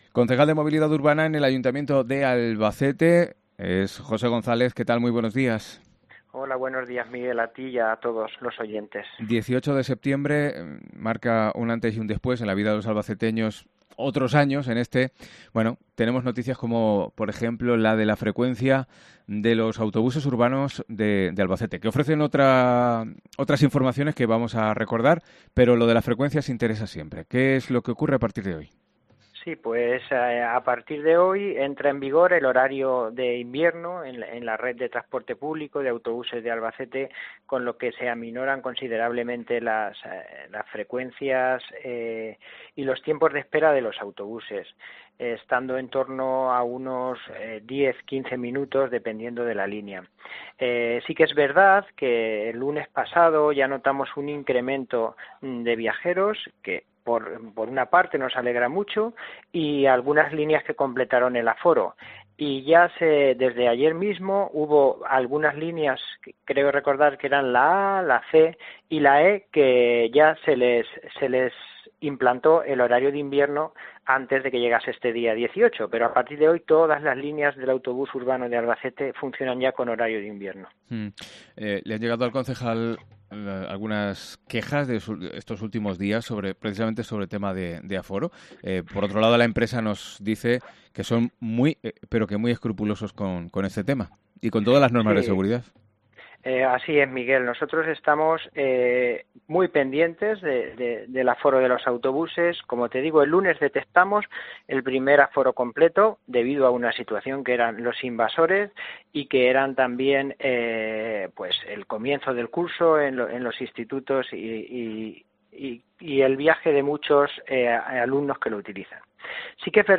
José González, concejal de Movilidad Urbana, comenta aspectos como la implantación de wifi en los buses urbanos o las sanciones impuestas por circular por estos tramos peatonales